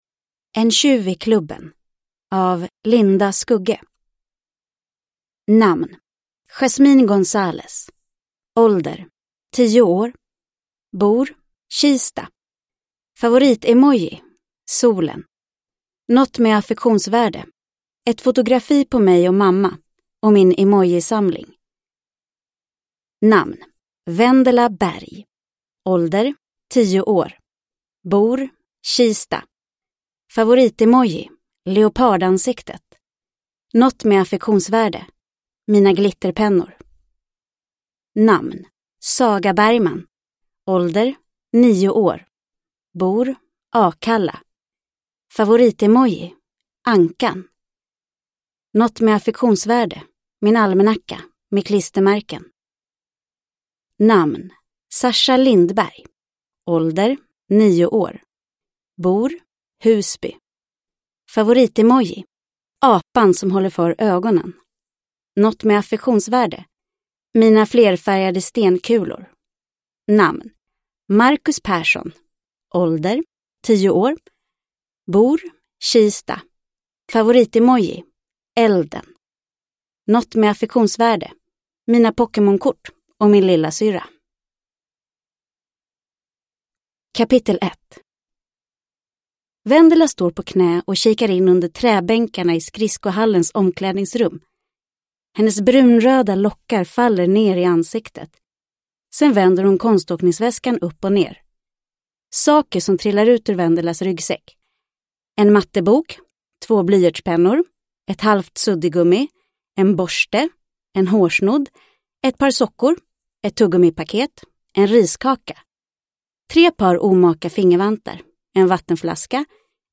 En tjuv i klubben – Ljudbok – Laddas ner